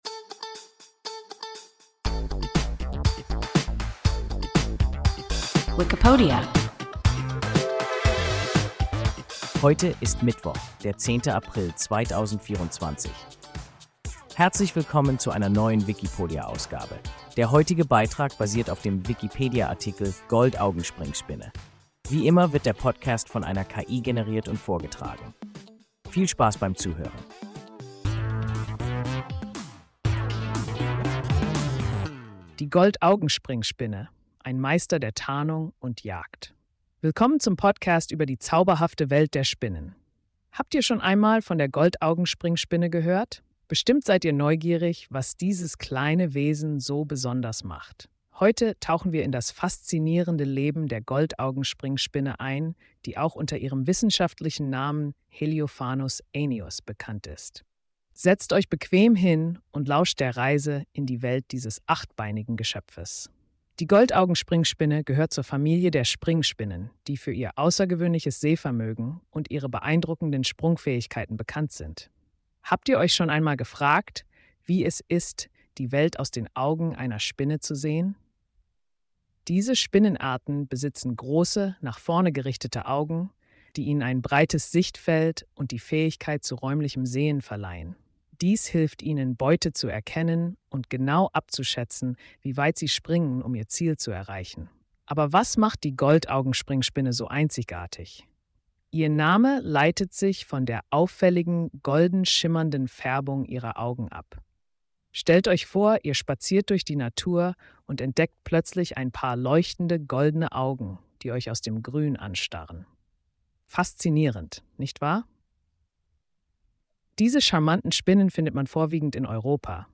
Goldaugenspringspinne – WIKIPODIA – ein KI Podcast